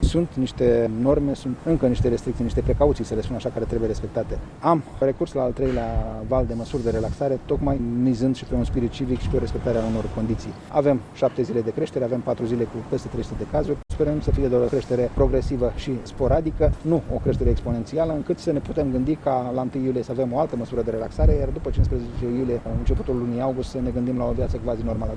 Iar ministrul Sănătăţii, Nelu Tătaru, speră că numarul mare de infectari din ultimele zile nu ne va duce la al doilea val al epidemiei. El spune că toate măsurile de relaxare s-au bazat şi pe colaborarea oamenilor, pe care se mizează şi în următoarea perioadă: